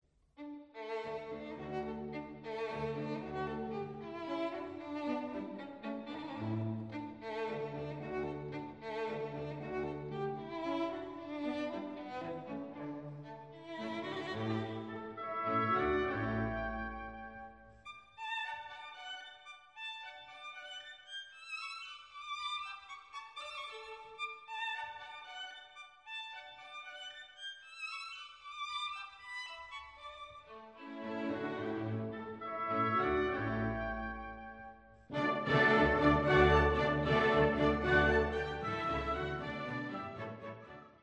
Violin